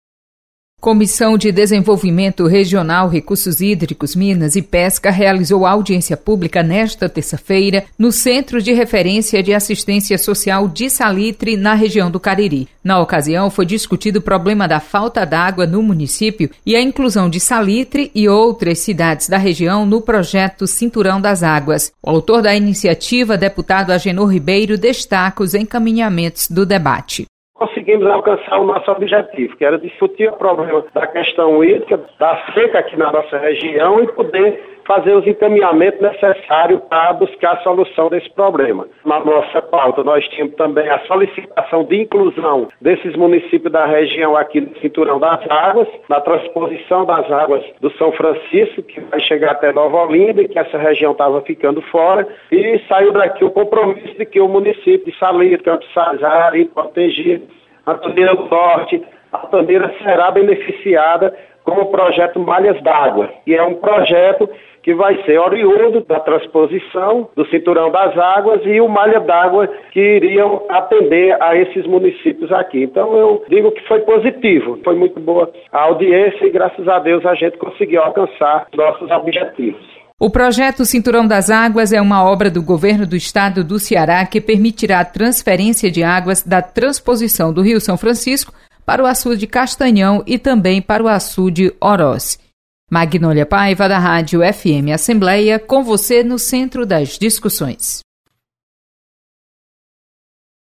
Comissão discute crise hídrica em Salitre. Repórter